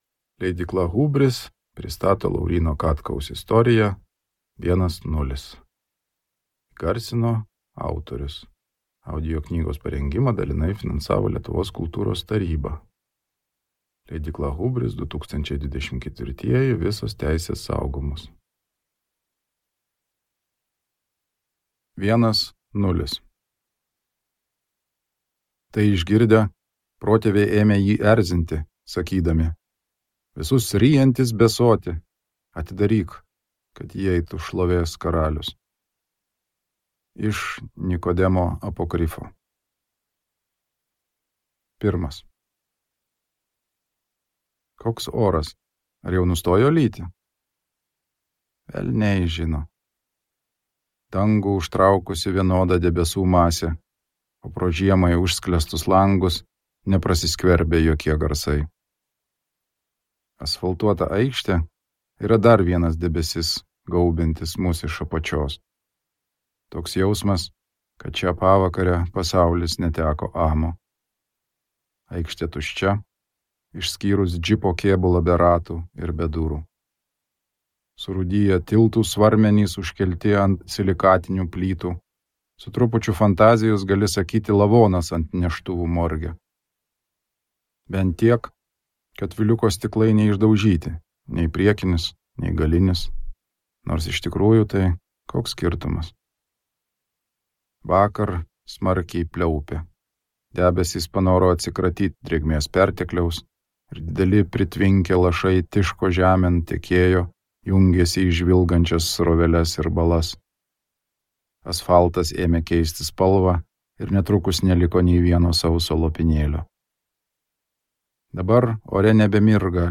audioknyga